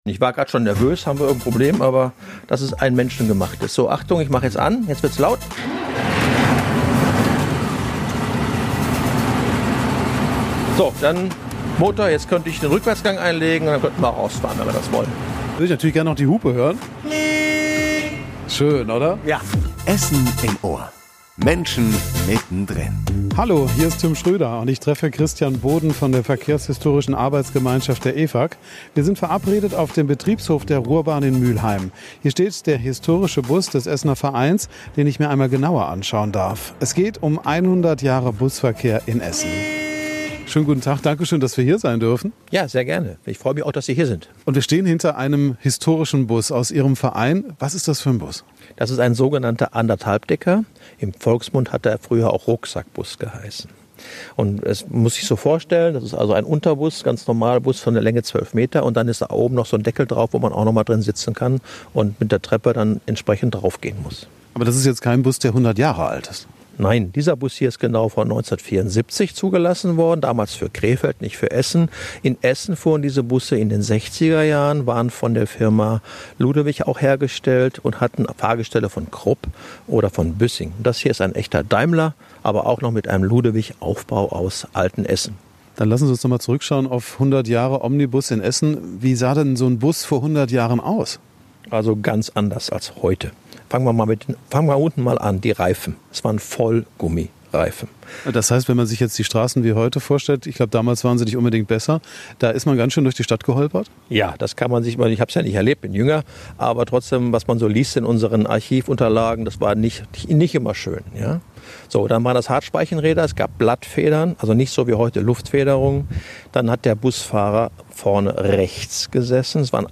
Das klingt richtig schön.